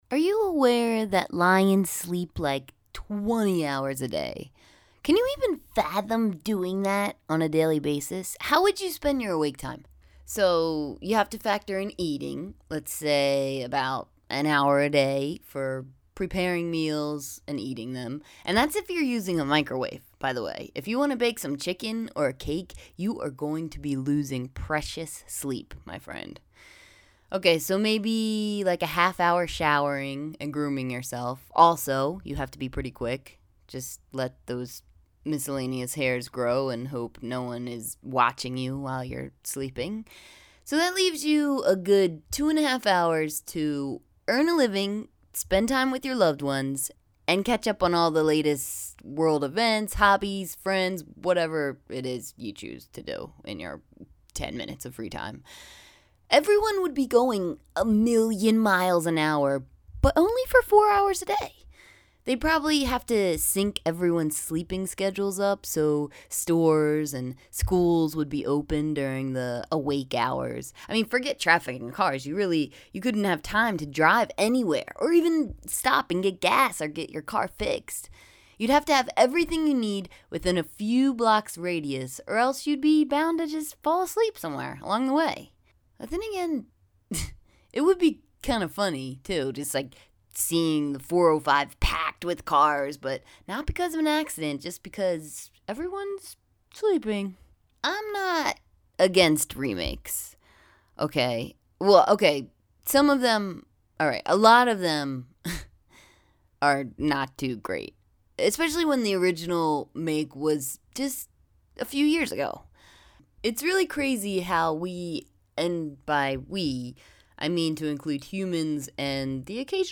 PRESS PLAY TO HEAR ME READ THIS BLOG TO YOU!